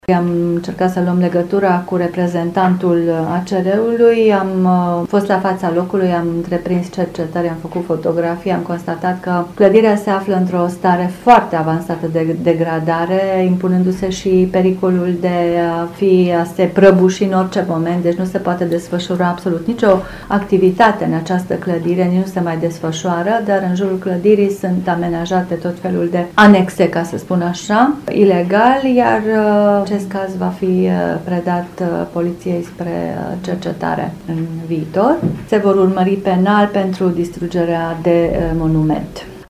Şefa Direcţiei, Kelemen Antonia Izabella a declarat că imobilul este într-o stare atât de gravă, încât “se poate prăbuşi în orice moment”: